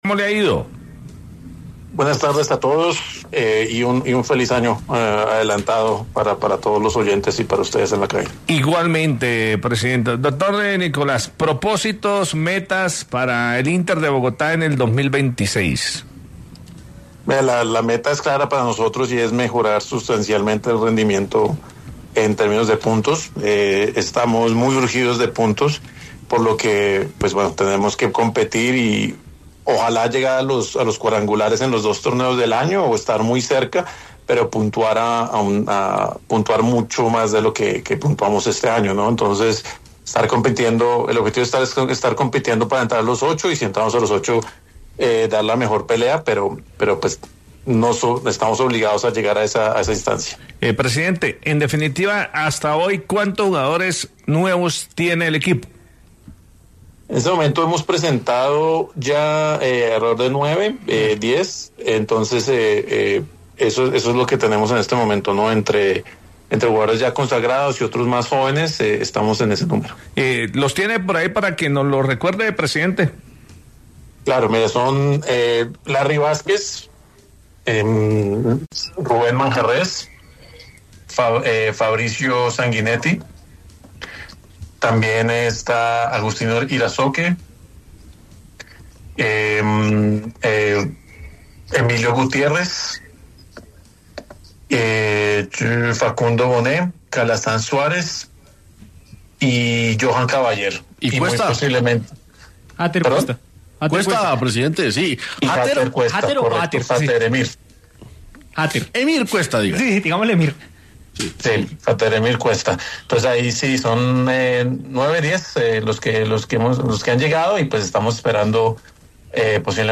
concedió una entrevista a El VBar